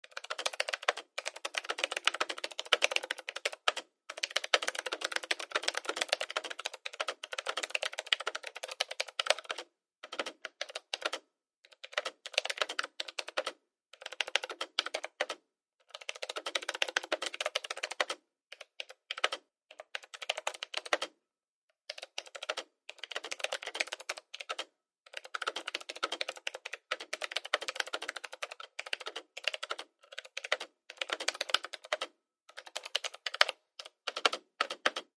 PcKeyboardFastTaps PE263002
PC Keyboard 2; Desktop Keyboard; Rapid / Steady Typing, Close Perspective.